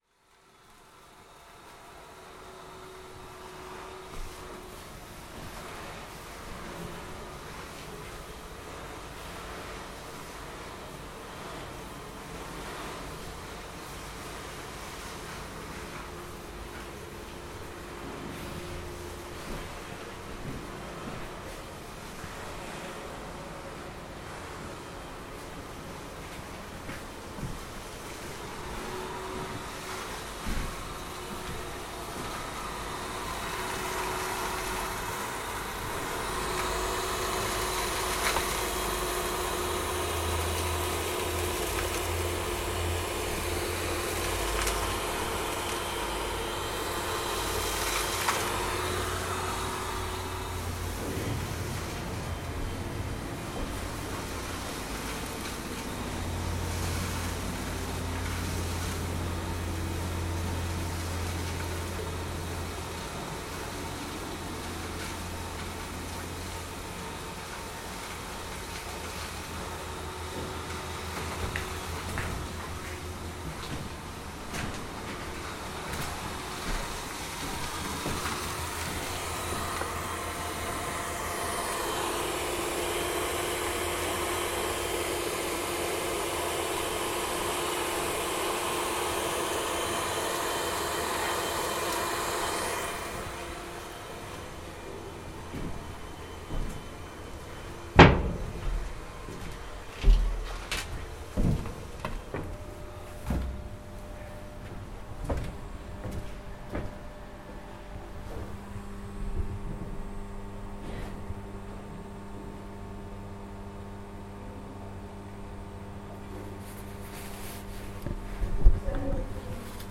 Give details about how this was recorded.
Engine room of an ice-breaking ship, Hamburg The Oevelgoenne ice-breaking ship - now a museum - in Hamburg, and a tour of the engine room, October 2014.